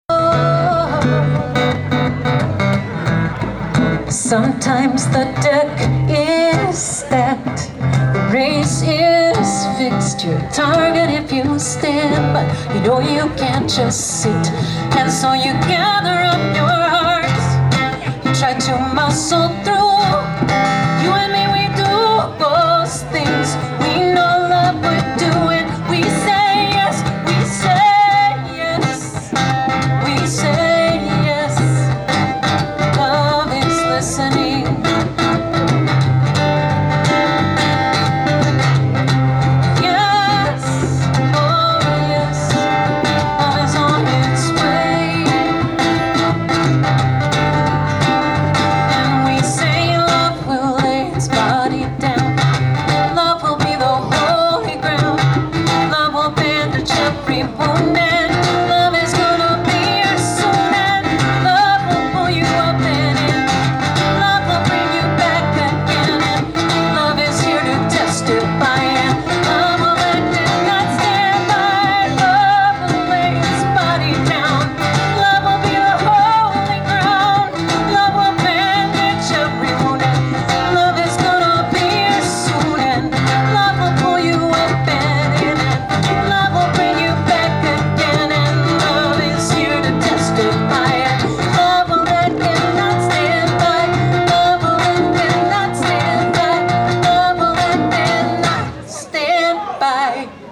on percussion